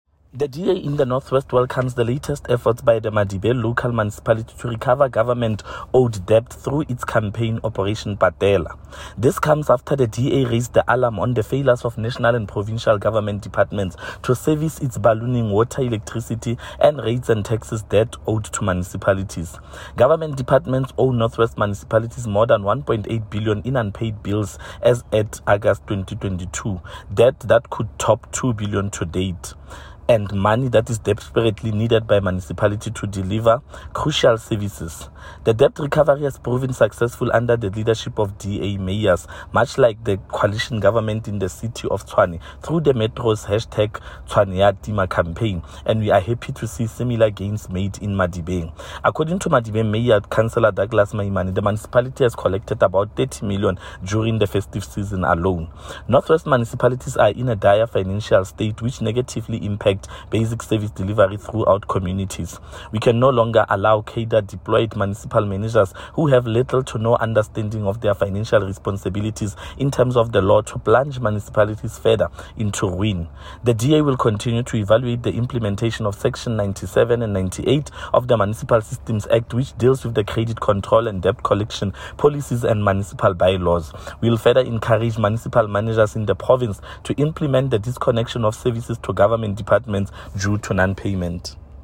Note to Broadcasters: Find linked soundbites in